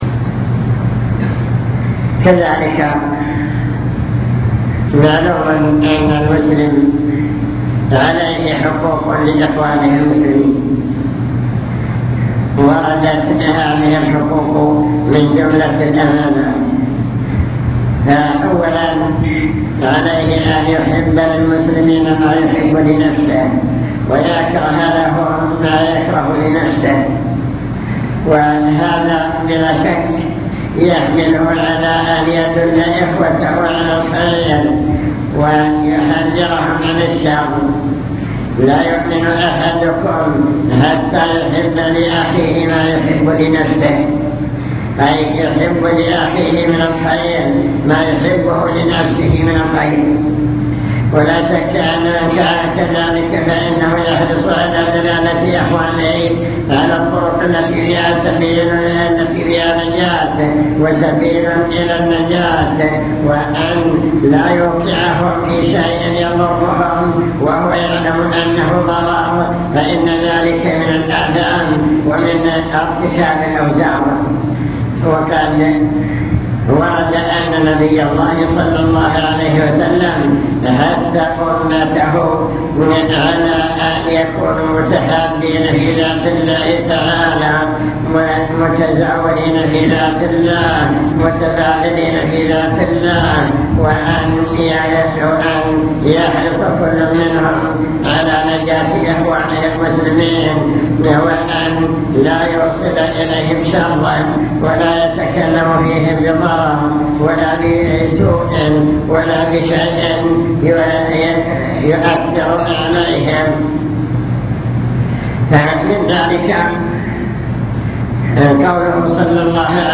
المكتبة الصوتية  تسجيلات - محاضرات ودروس  أطب مطعمك